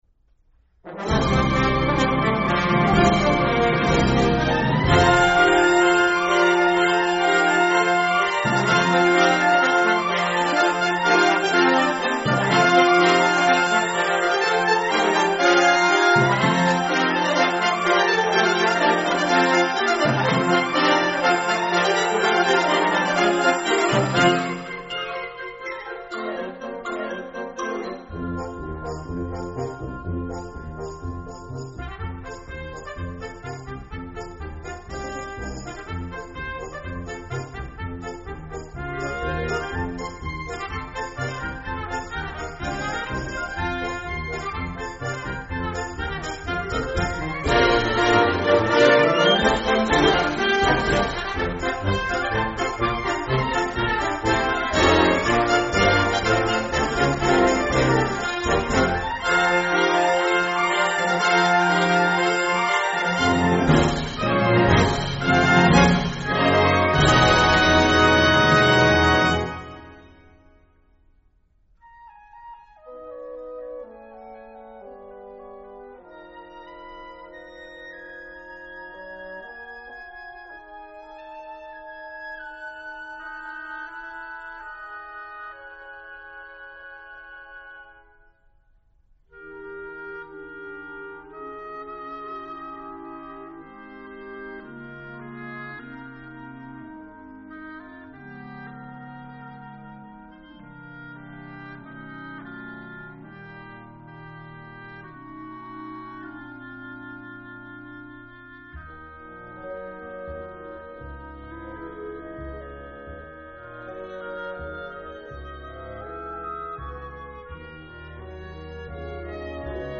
Que ben soa a canción de Oh Susanna tocada por unha orquestra sinfónica!